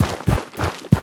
biter-walk-big-7.ogg